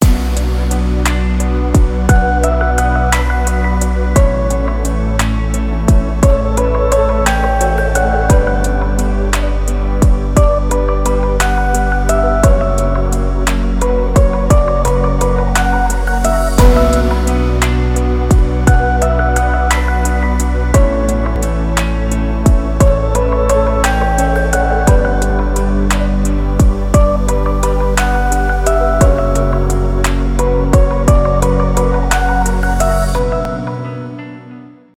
без слов , романтические
поп
спокойные